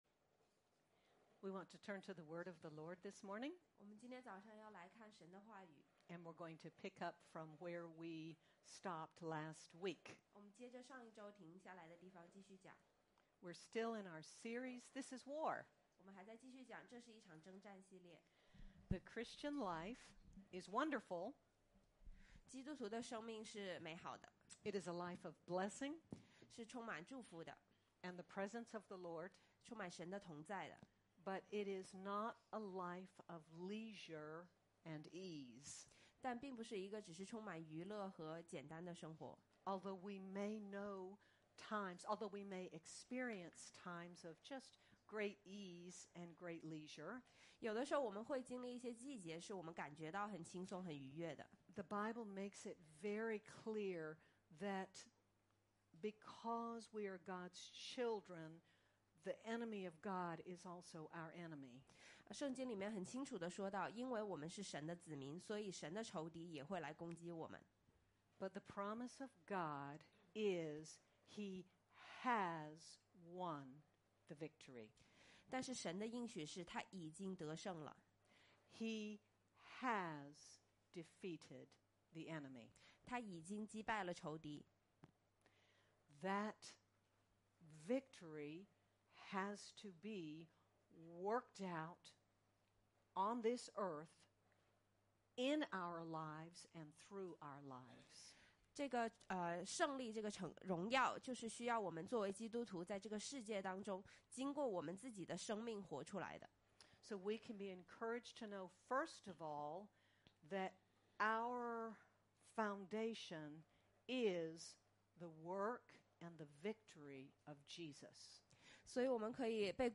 Through the examples of Paul and Silas, Joshua, and Jehoshaphat we learn the position of praise and how to overcome. Sermon by